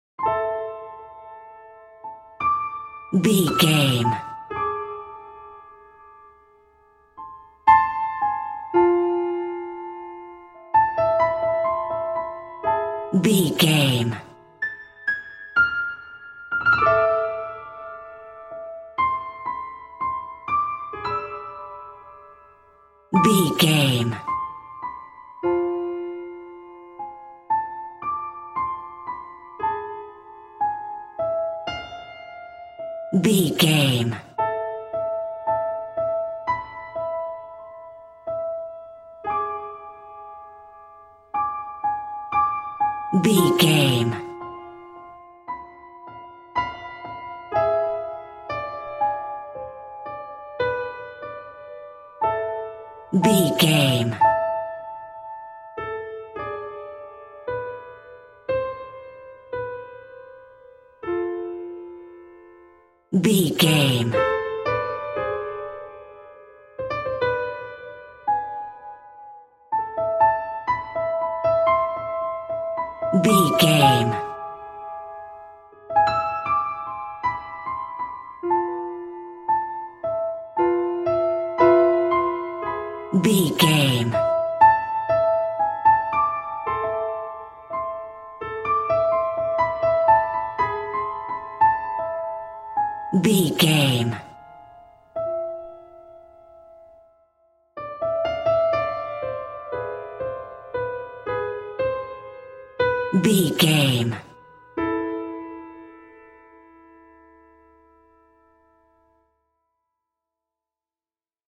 Short Horror Piano Cue.
Aeolian/Minor
tension
ominous
eerie